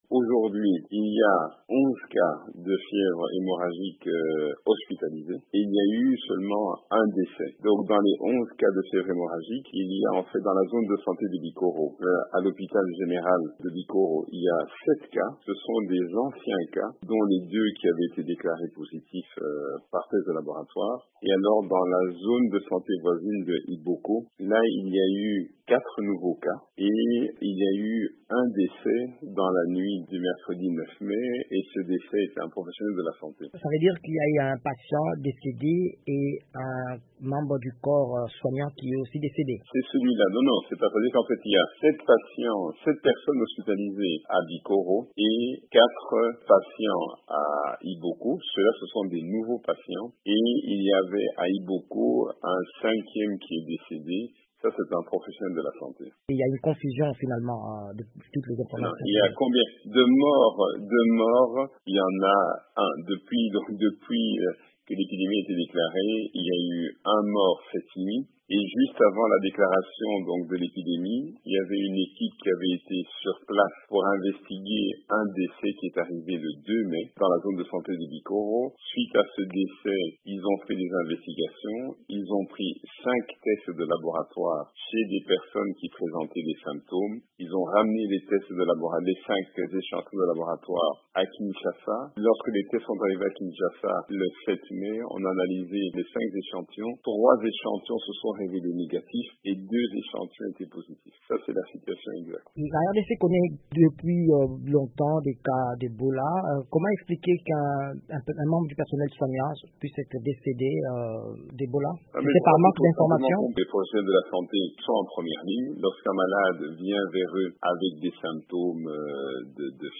Il n’y a jusque-là qu’un seul mort dans l’épidémie d'Ebola déclarée il y a deux jours en RDC, a précisé le ministre de la santé Dr. Oly Ilunga, dans un entretien à VOA Afrique.